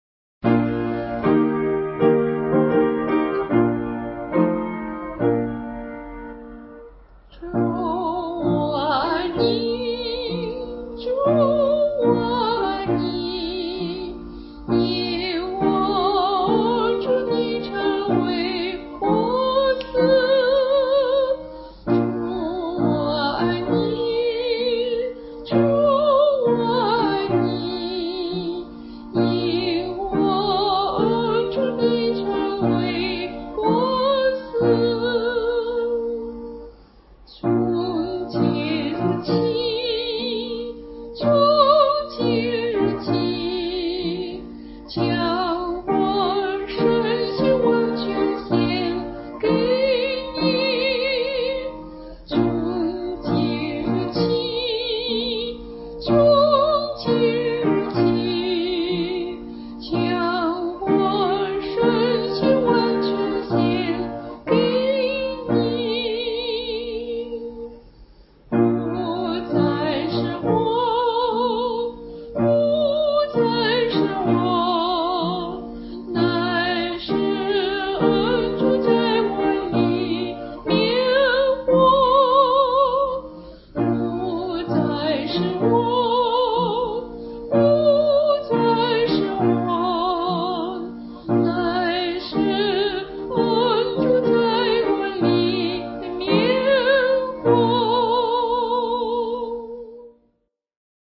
704b伴奏